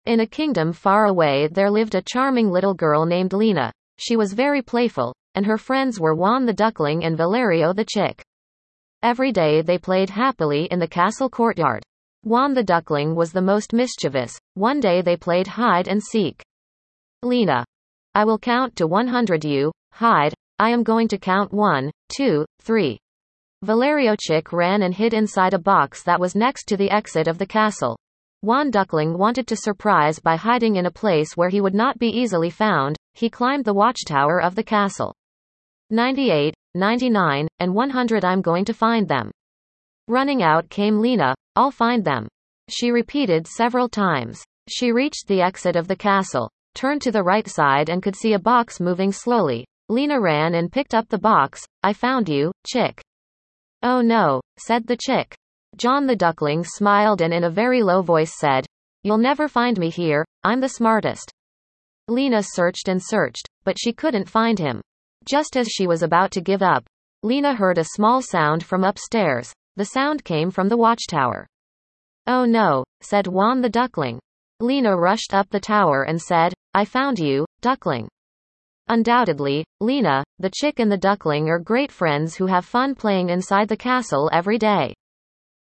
Enjoy it! Here’s the audio story!